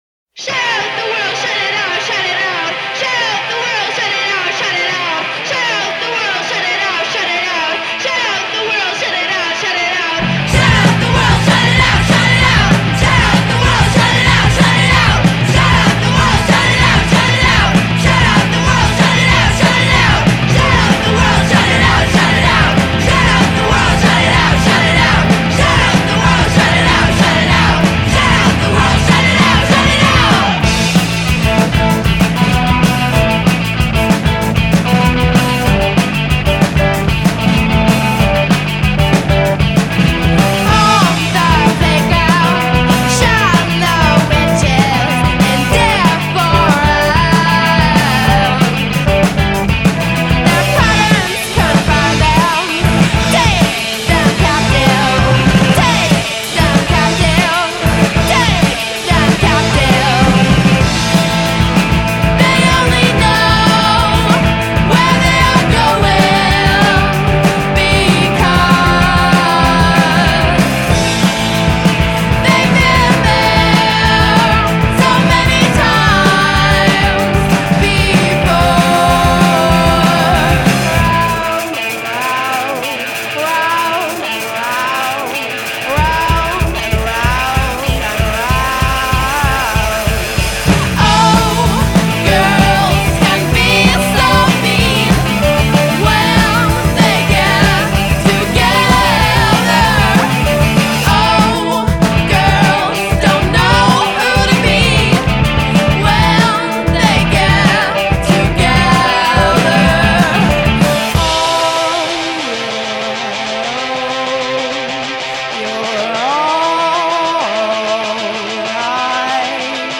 Canzoni da ballare una dopo l'altra
e dalle atmosfere new wave belle tirate.